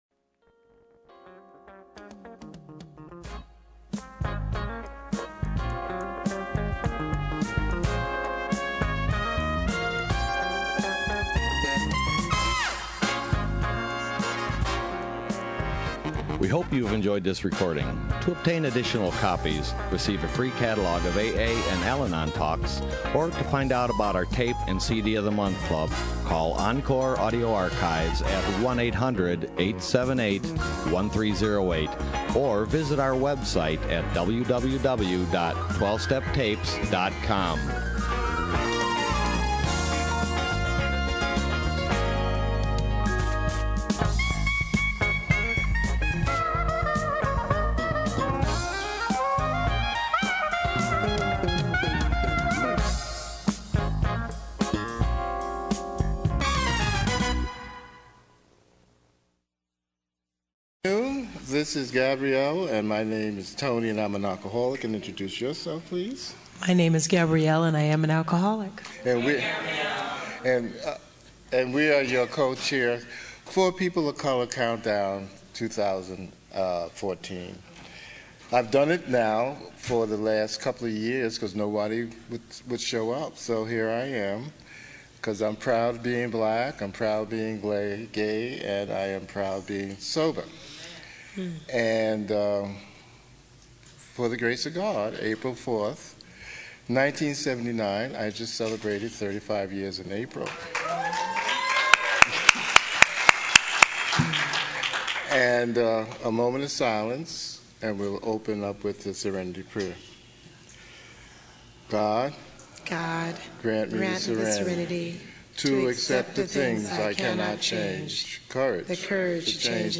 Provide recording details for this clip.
People of Color Meeting &#8211